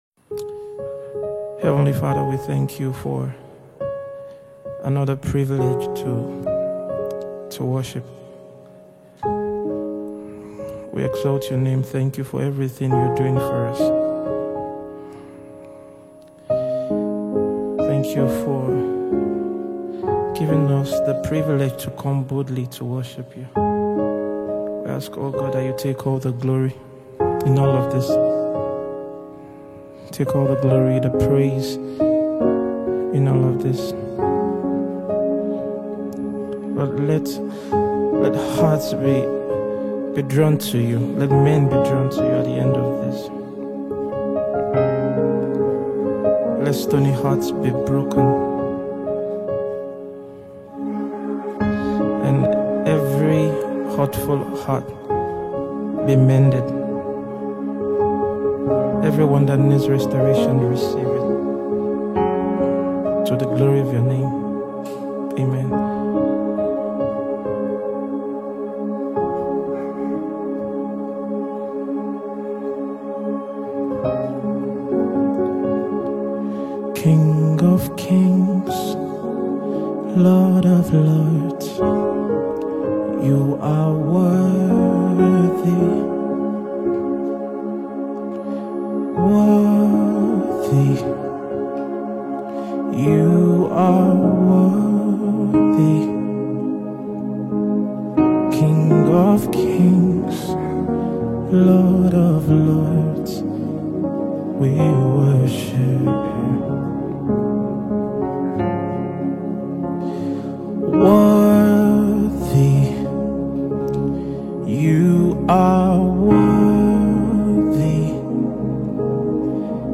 gospel
aims to spread blessings through kingdom-inspired music.